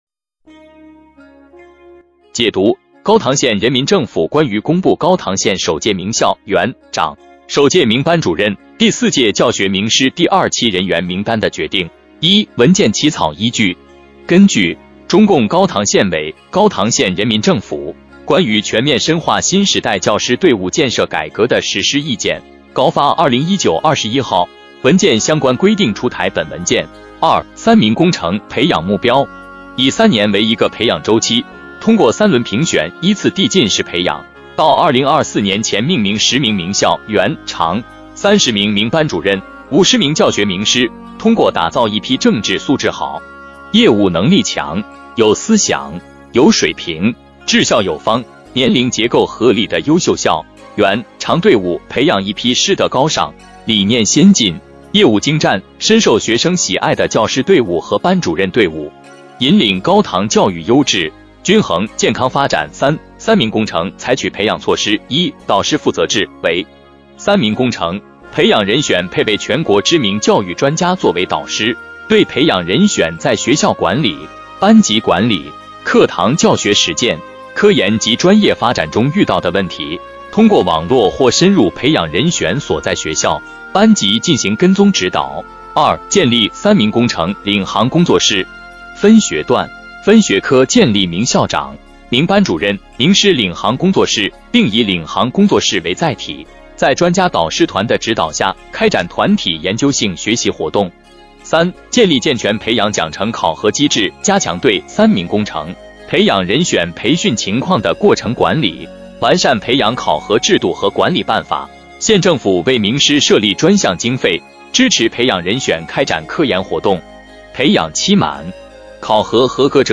【音频解读】高唐县人民政府关于公布高唐县首届名校（园）长首届名班主任第四届教学名师第二期人员名单的决定